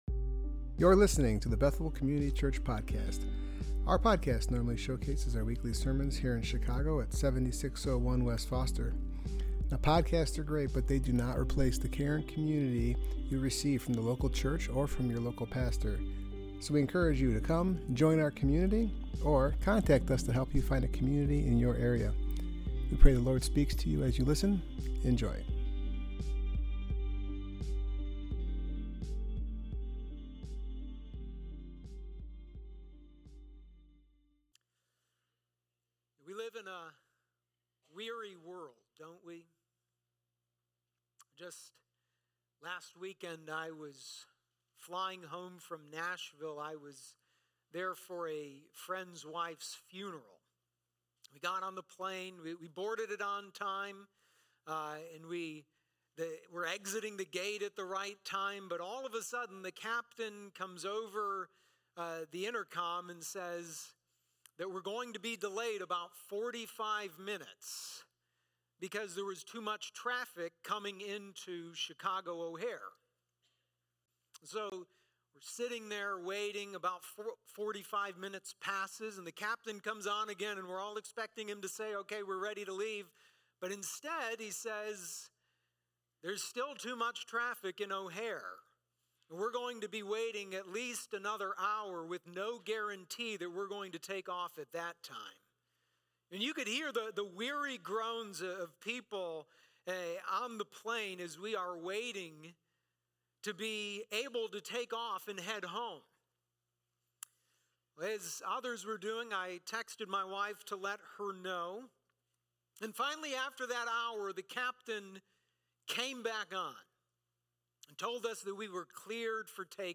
Passage: Luke 1:46-56 Service Type: Worship Gathering Topics